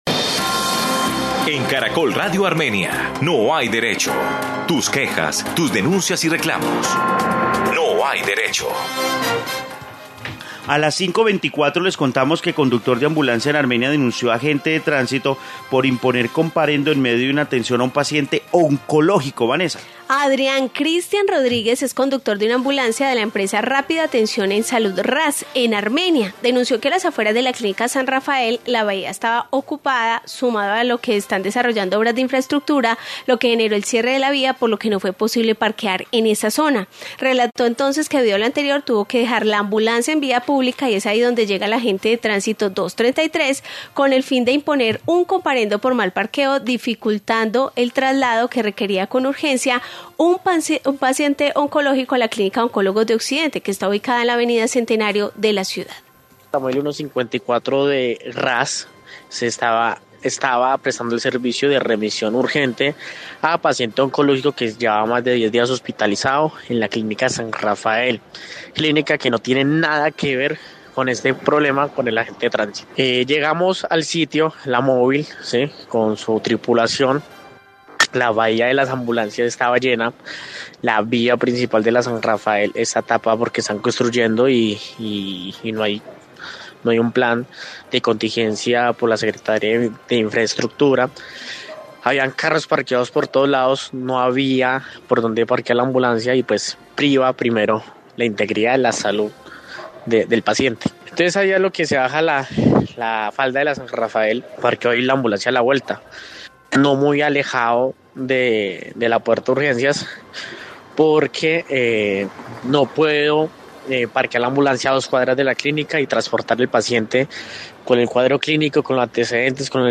Informe sobre denuncia de conductor de ambulancia